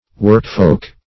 Workfolk \Work"folk`\, n. People that labor.